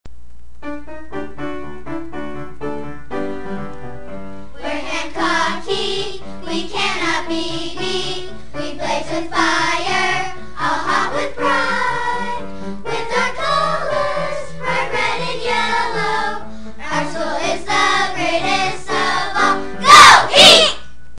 Listen to the school song - Performed by the 2004 - 2005 Hancock Choir
schoolsong_choir.wav